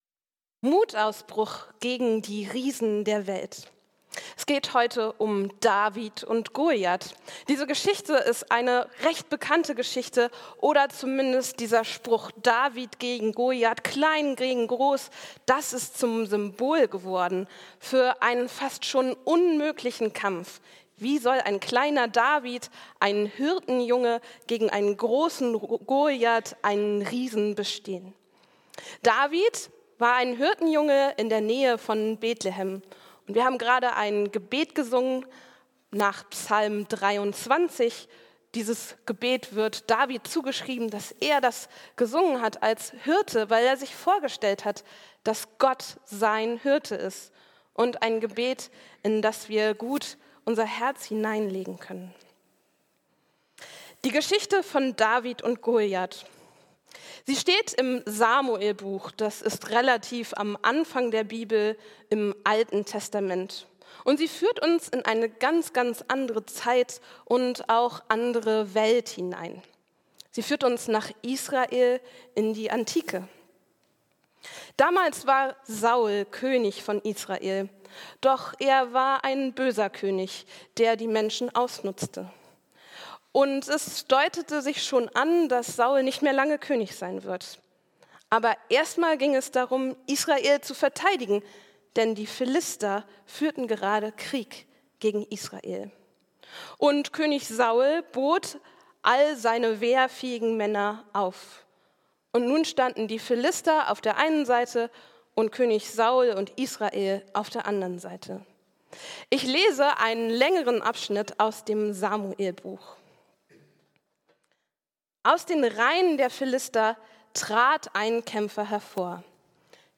In dieser Predigt widmen wir uns einer der bekanntesten Geschichten der Bibel: David und Goliath.